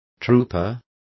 Complete with pronunciation of the translation of troopers.